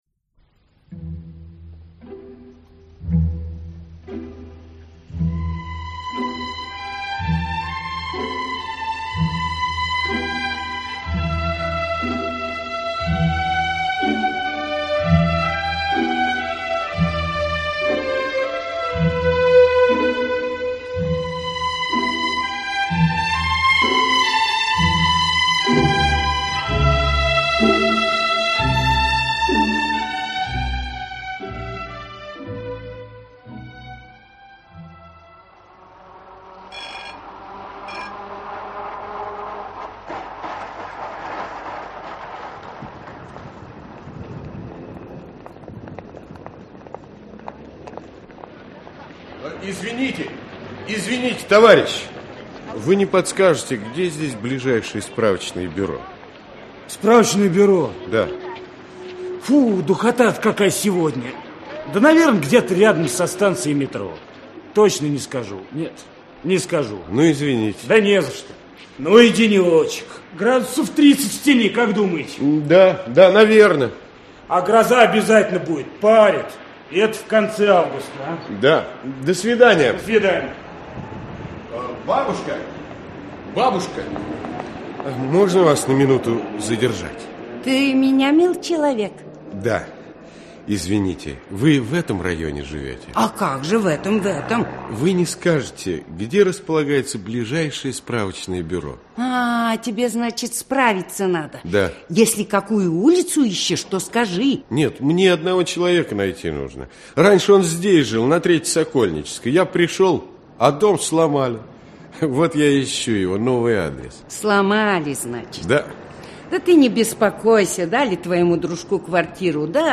Радиопостановка. После долгих лет разлуки Дмитрий Шилов встретился со своим фронтовым другом Аркадием.